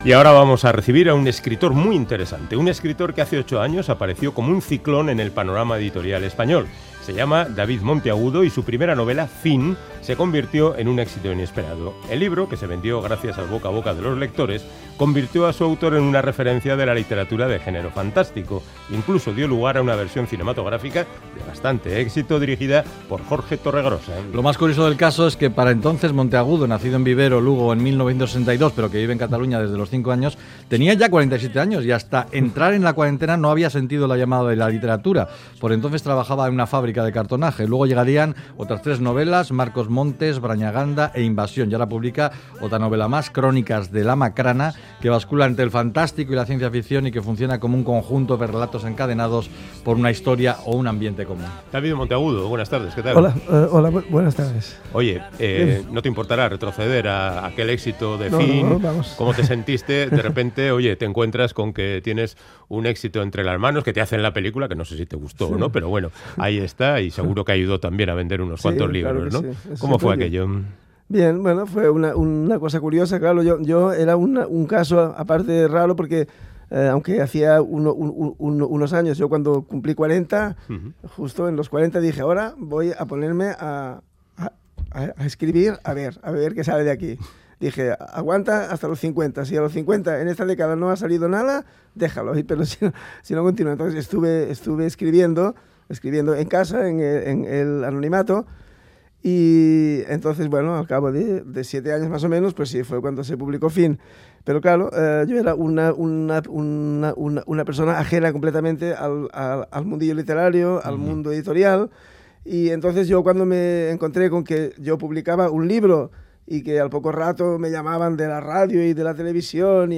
Audio: Charlamos con el escritor catalán David Monteagudo, autor de la famosa novela Fin, de su nuevo libro, relatos que parcen una novela, Crónicas del Amacrana